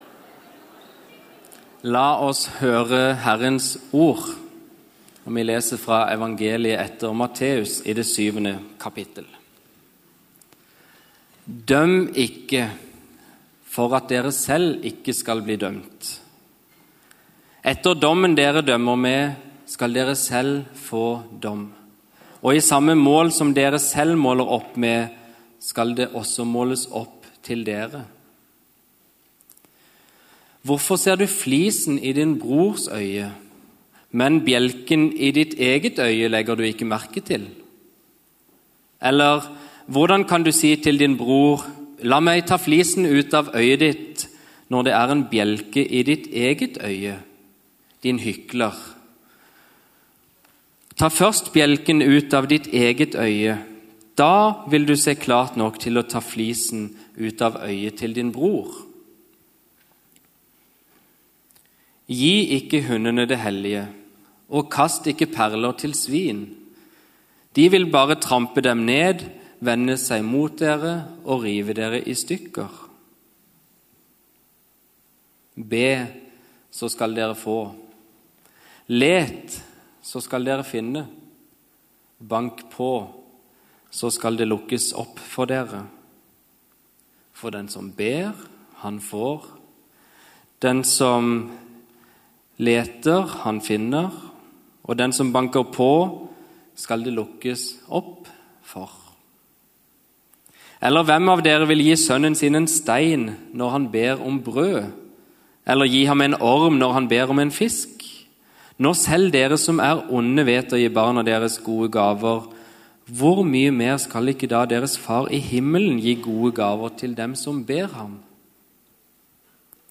Taler fra Storsalen menighet